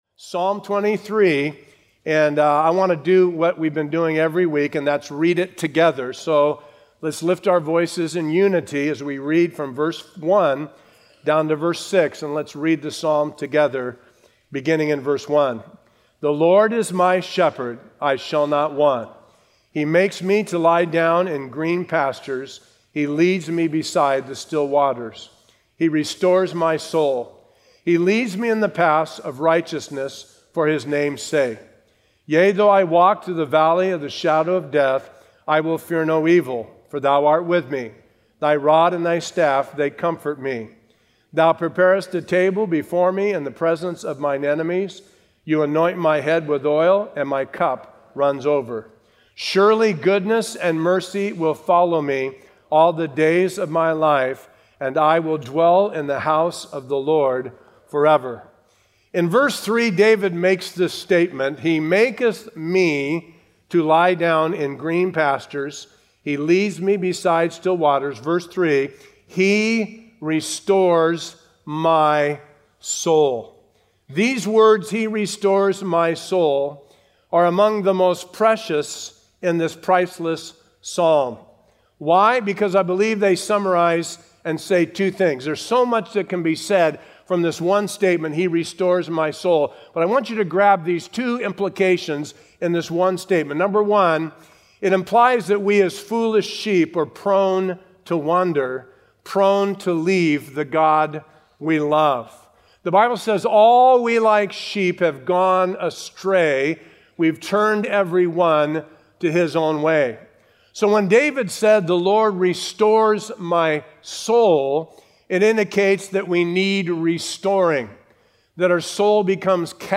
A verse-by-verse expository sermon through Psalms 23:3